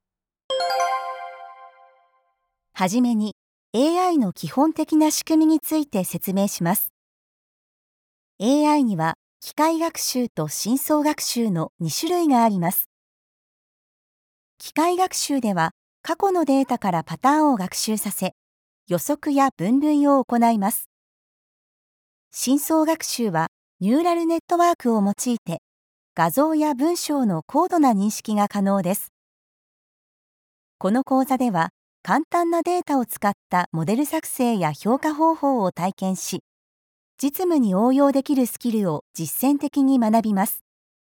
Démo commerciale
Apprentissage en ligne
Microphone | Audio Technica AT4040